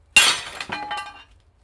• small glass broken with a hammer - garage.wav
An old storage demolition snips, recorded with a Tascam DR 40. Broken a small window.
small_glass_broken_with_a_hammer_-_garage_Qds.wav